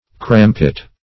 crampit - definition of crampit - synonyms, pronunciation, spelling from Free Dictionary
Crampit \Cram"pit\ (kr[a^]m"p[i^]t), n. (Mil.)